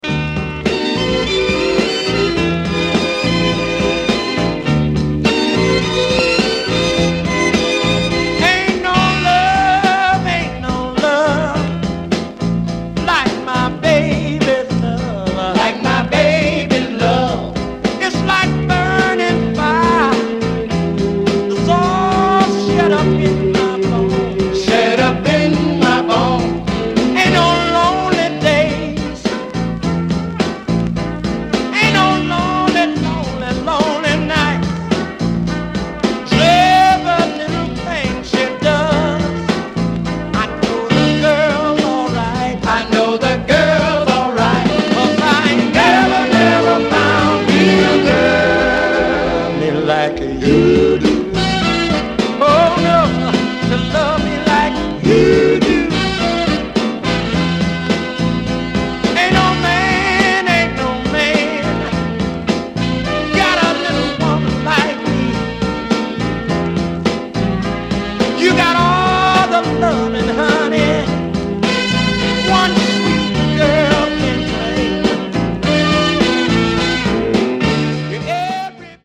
Sweet soul